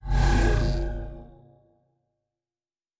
pgs/Assets/Audio/Sci-Fi Sounds/Doors and Portals/Teleport 6_2.wav at master
Teleport 6_2.wav